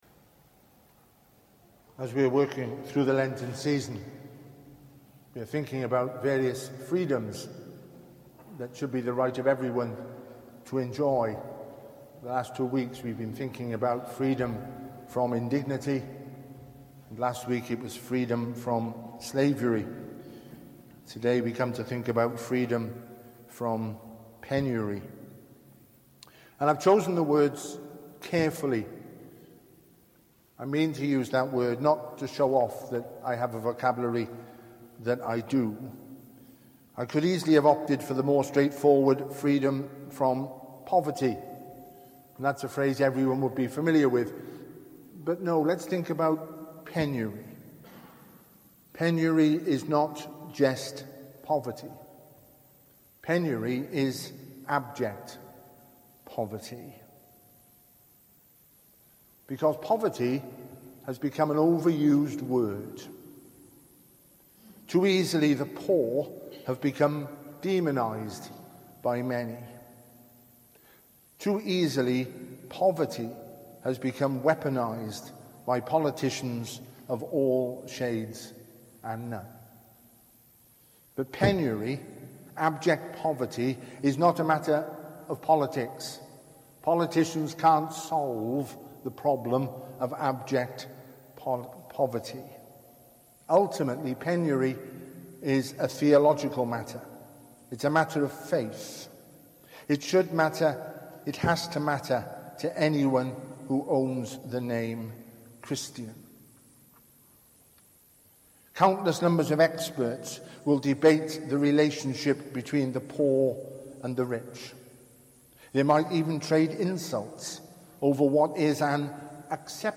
Lent 2018 Service Type: Family Service ‘…The poor you always have with you…’